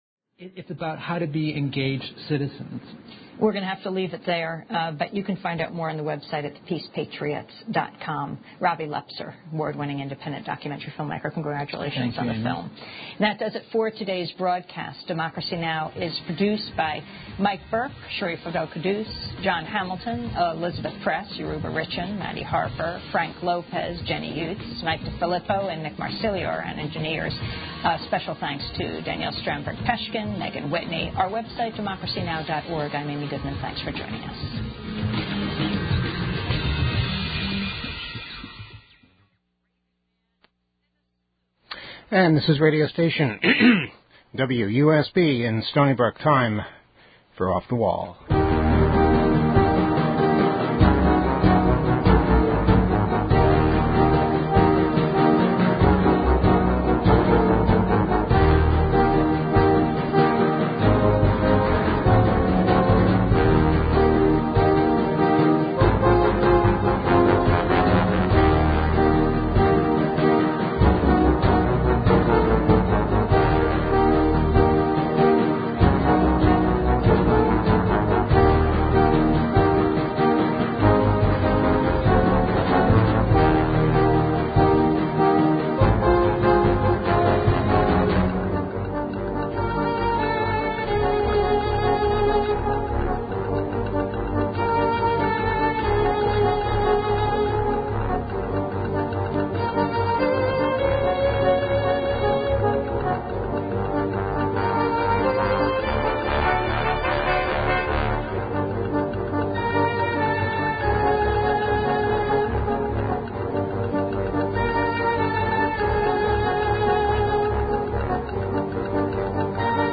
A live interview with Amy Goodman from "Democracy Now!"